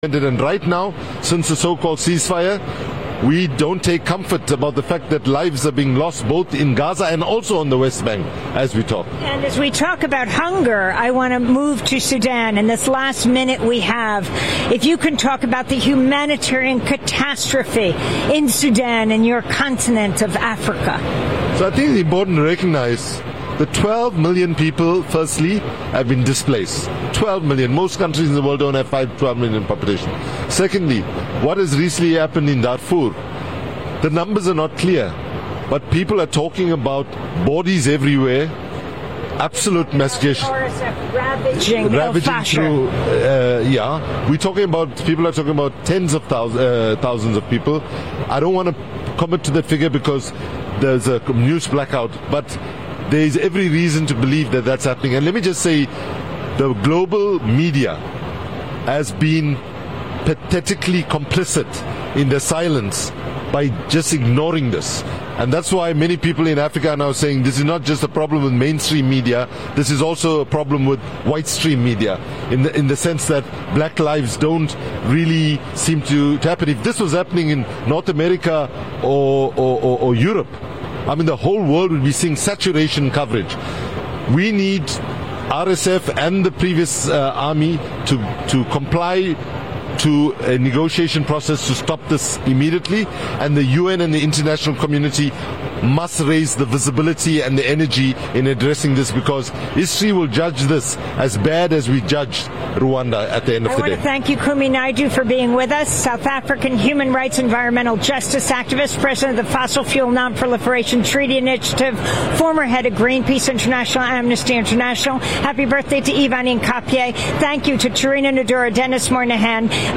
Evening News on 11/18/25